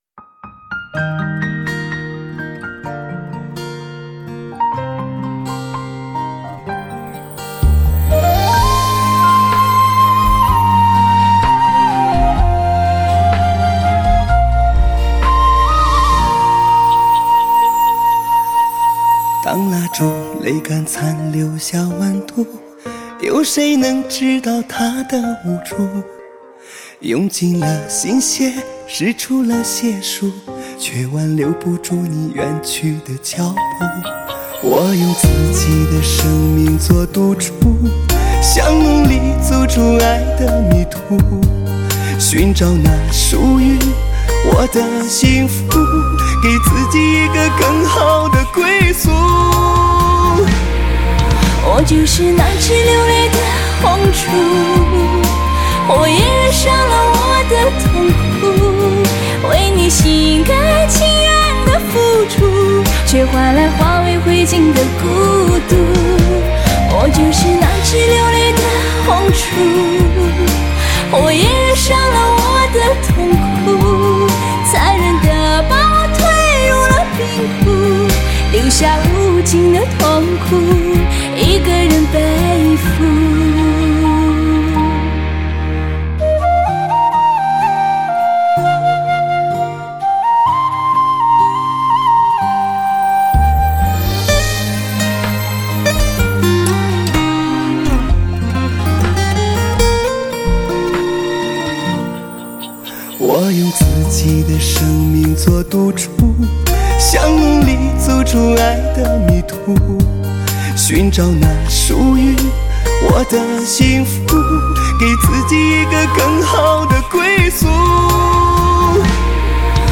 3D音效+环绕360°